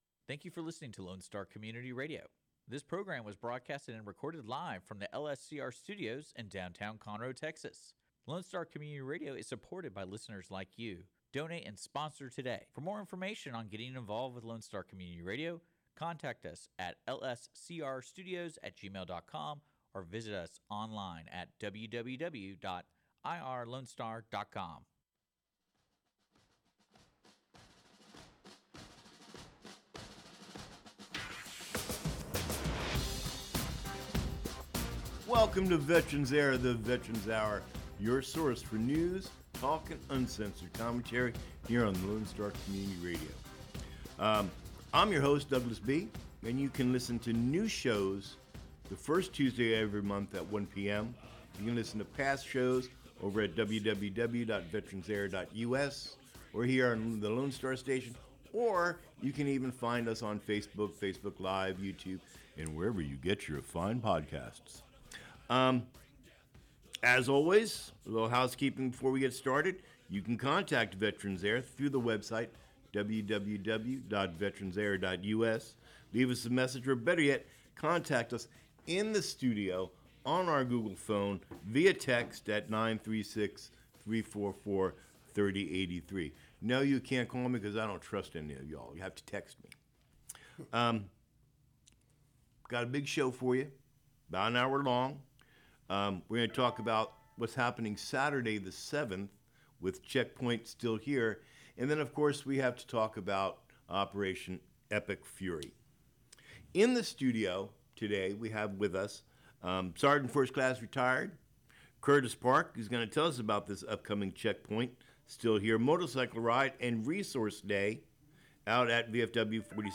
Mission: To bring news, talk and commentary of interest to veterans and their families as well as our active duty service members. The show covers current proposed legislation, interviews with experts on topics of major interest to veterans, call-ins from our listeners and community events and activities.